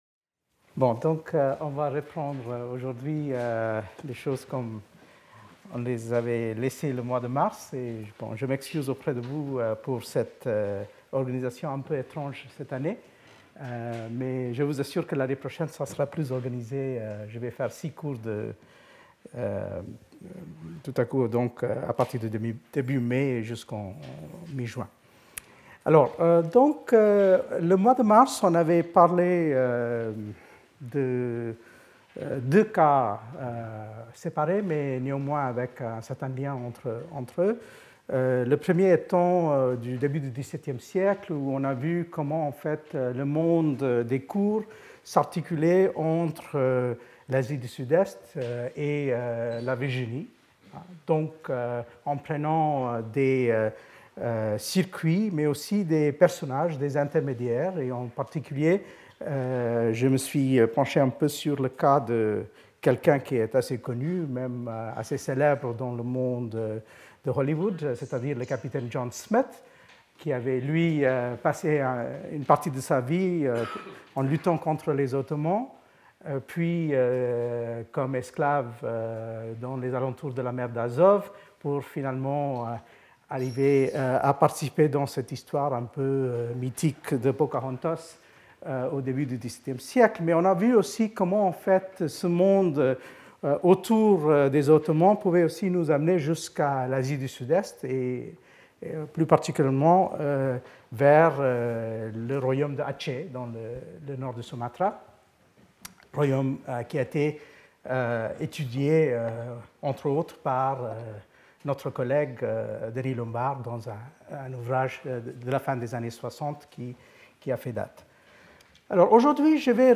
Intervenant(s) Sanjay Subrahmanyam Professeur, Université de Californie de Los Angeles et Collège de France
Cours